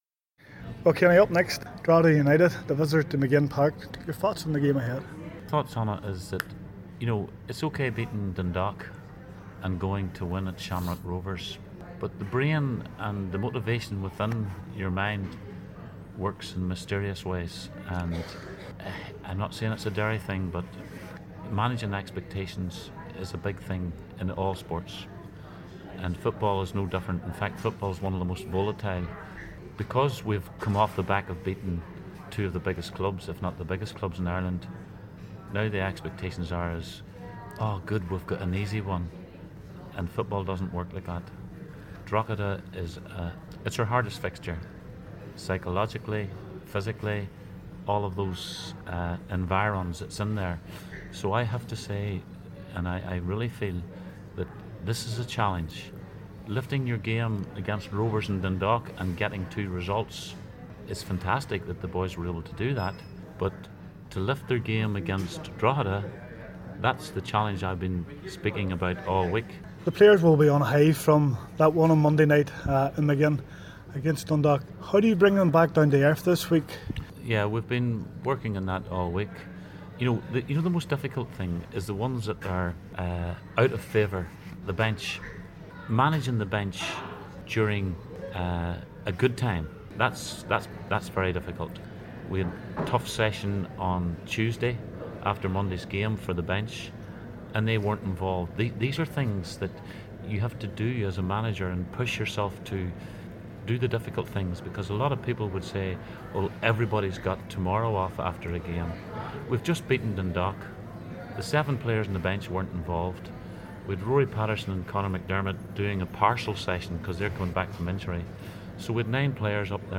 Drogheda Utd are the Visitors to Maginn this Saturday afternoon. Kenny Shiels believes this will be their hardest game psychology and physically for his charges. Interview